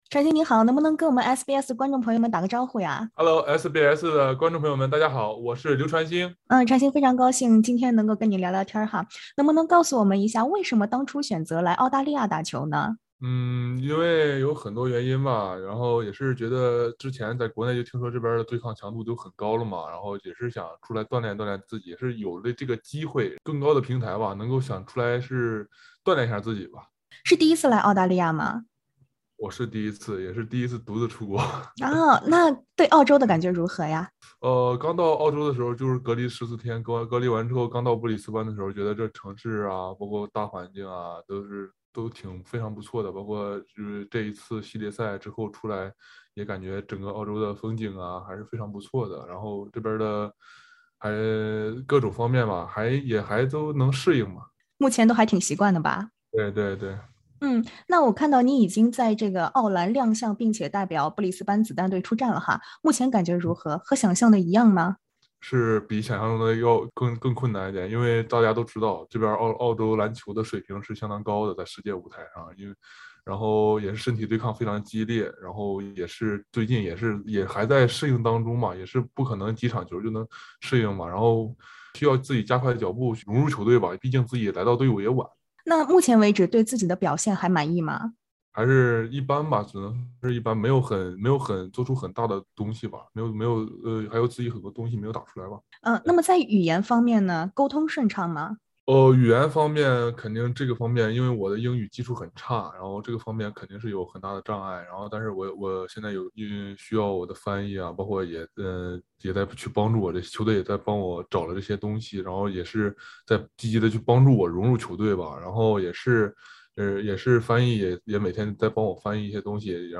【專訪】劉傳興澳籃賽季首戰 回應比較直言“專注自己”